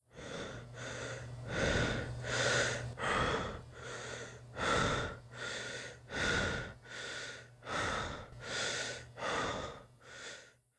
Player_HeavyBreathingLoop.wav